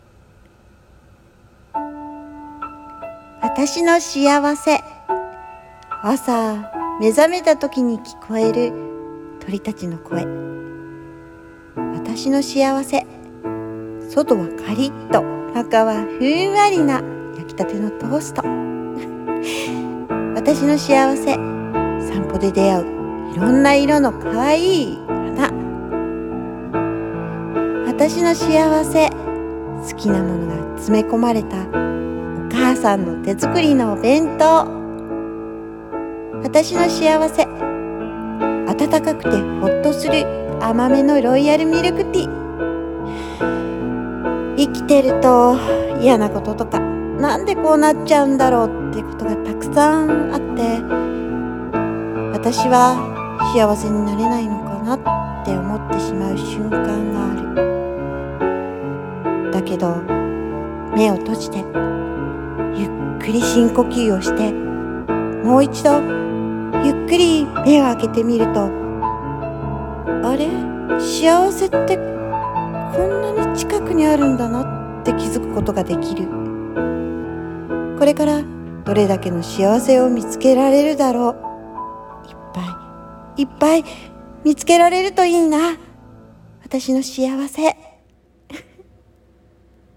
】しあわせ【一人声劇】 読み手：。